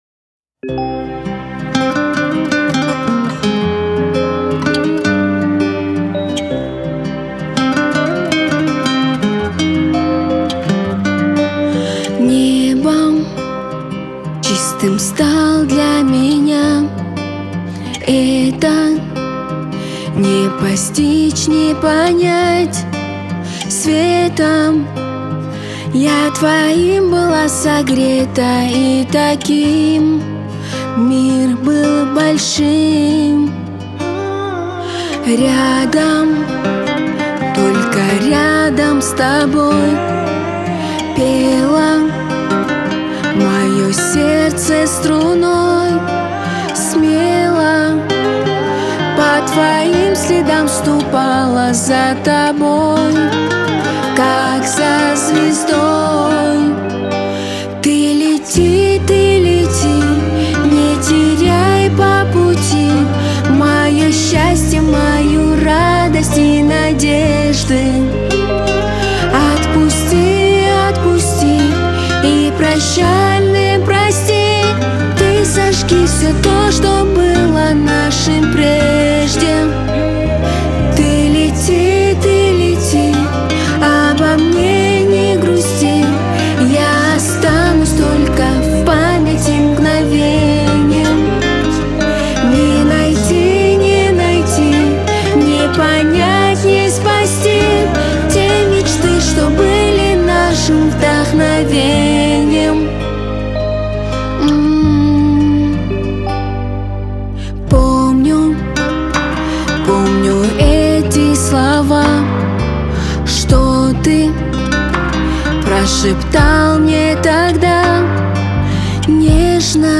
Скачать музыку / Музон / Кавказская музыка 2024